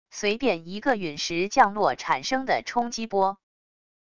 随便一个陨石降落产生的冲击波wav音频